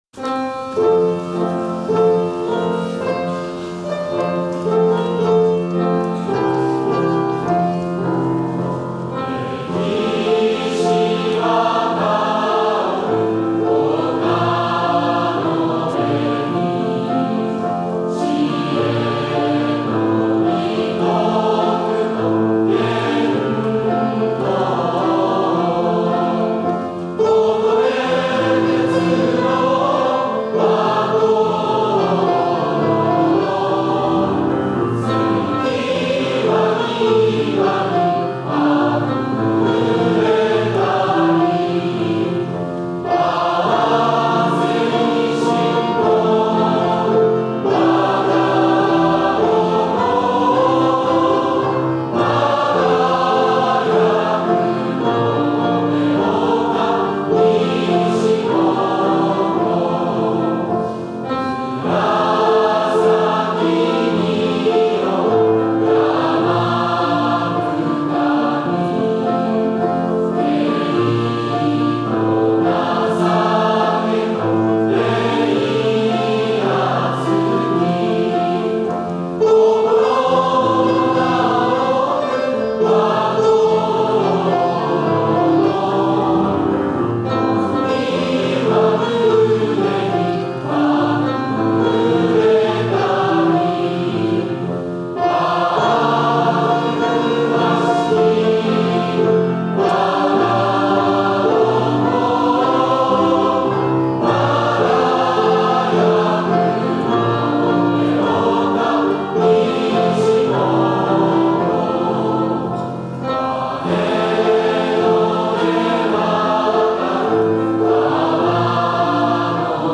歌付き